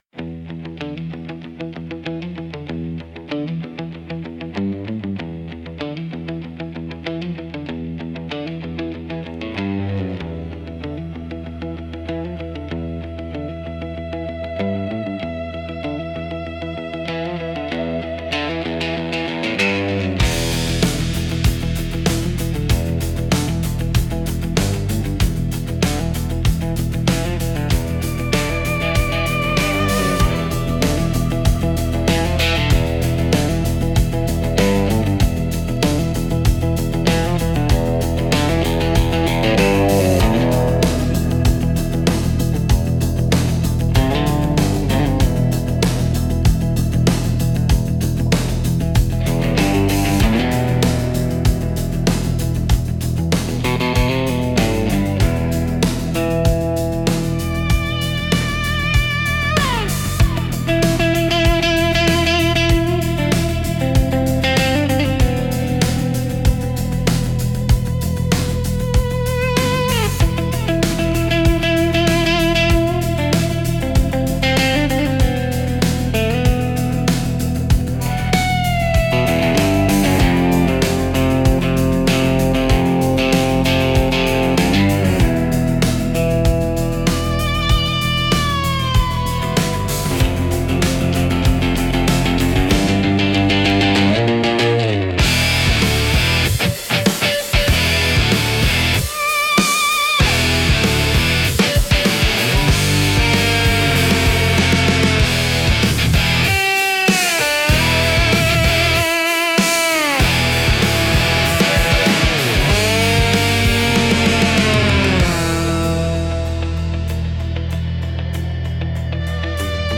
Instrumental - Where the Pavement Breathes 4.09